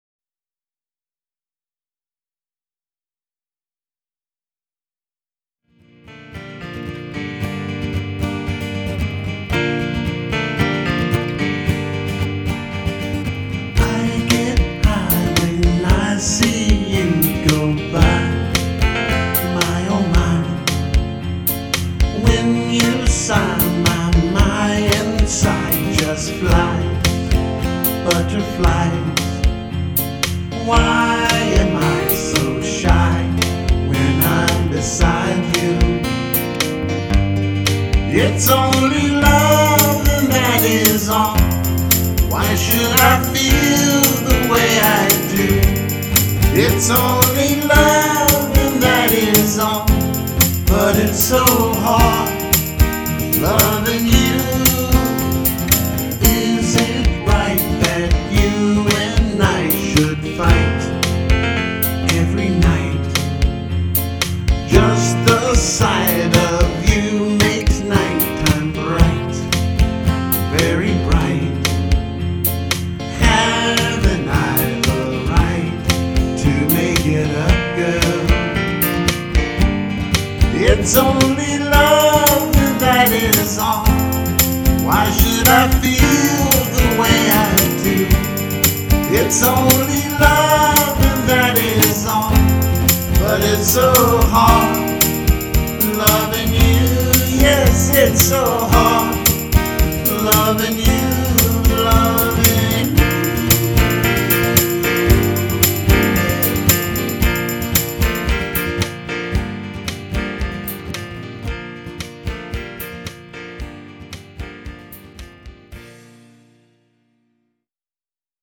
AT THE STAE FAIR OF TEXAS 2010